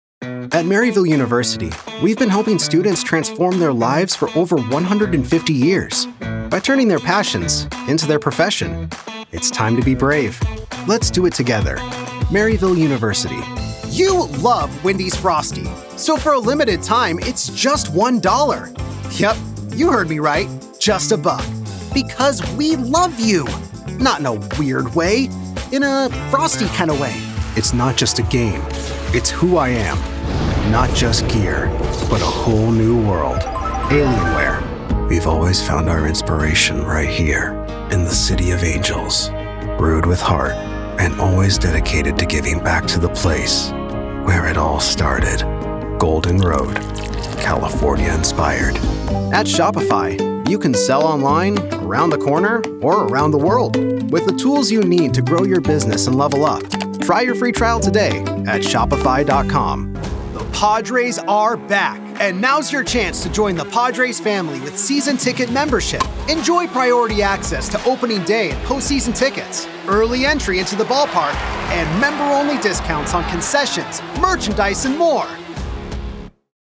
Young Adult, Adult, Mature Adult
I record with a Neumann TLM-103 microphone with an Solid State Logic SSL2 audio interface. I record in a small room padded from floor to ceiling with moving blankets.
standard us | character
standard us | natural
COMMERCIAL 💸
assertive/confident/bold
conversational
upbeat
warm/friendly